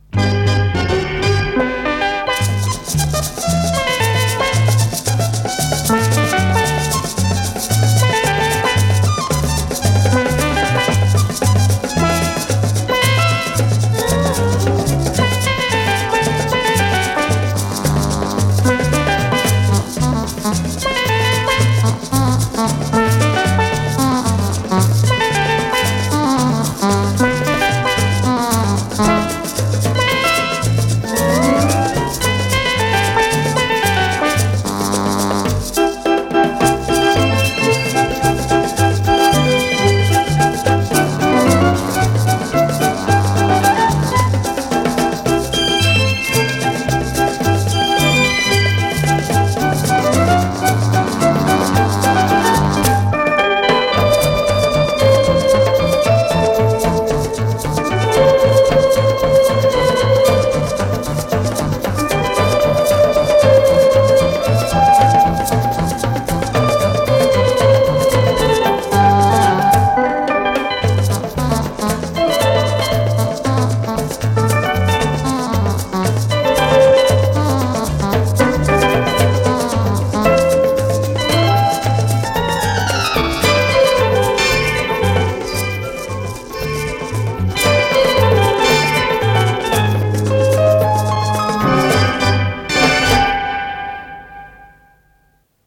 с профессиональной магнитной ленты
ПодзаголовокТанец, соль мажор
ВариантДубль моно